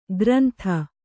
♪ grantha